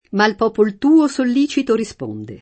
sollecito [Sollito] agg. («pronto») — latinismo ant. sollicito [Sollito]: Ma ’l popol tuo sollicito risponde [